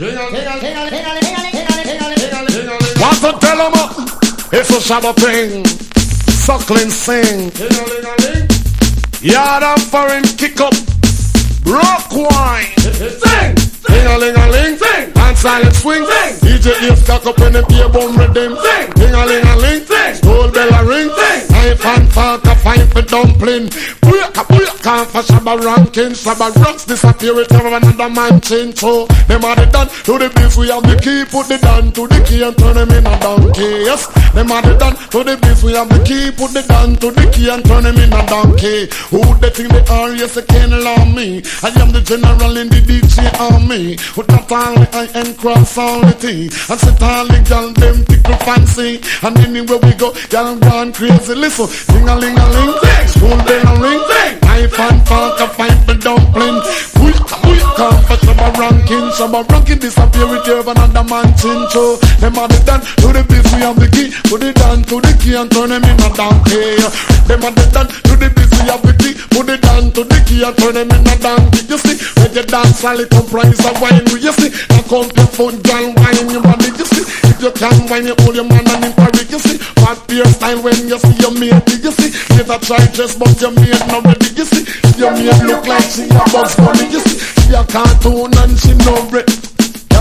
• REGGAE-SKA
当時”世界で最もセクシーな声を持つ男”と言われた生粋のラガマフィンによる90年代の重要シングルの1枚！
所によりノイズありますが、リスニング用としては問題く、中古盤として標準的なコンディション。
チープな打ち込みトラックとキャッチーなフレーズがかっこいい、ダンスホール・ファン必携の1枚！